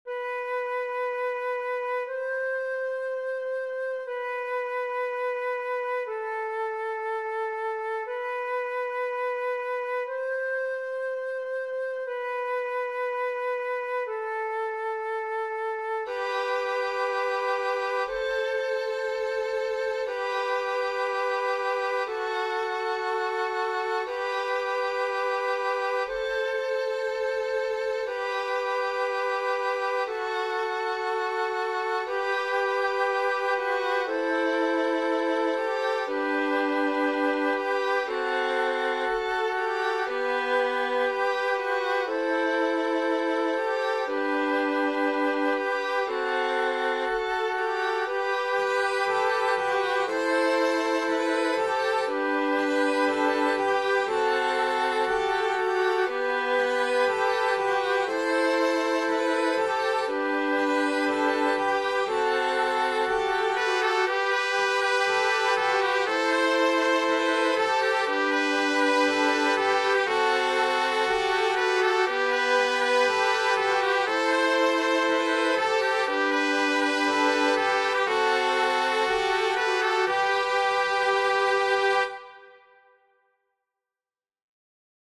a joyful, prayerful romp for absolutely everybody.
A cappella